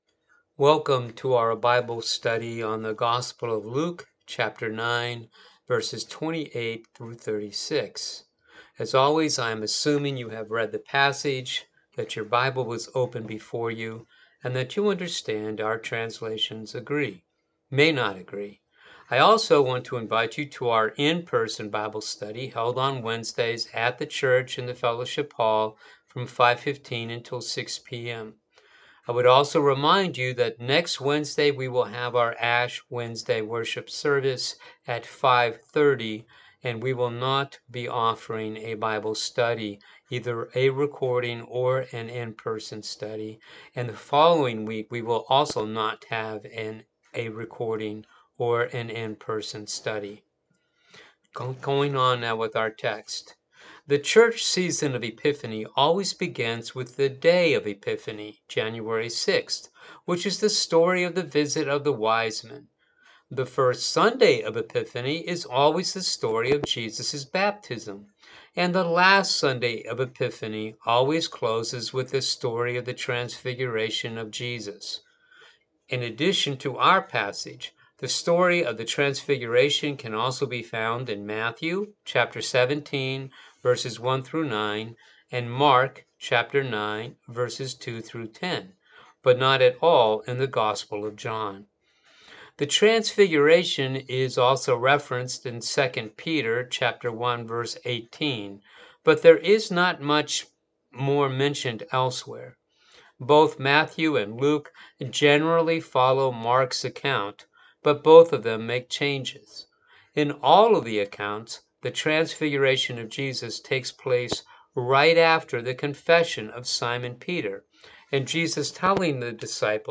Bible Study for the February 27 Service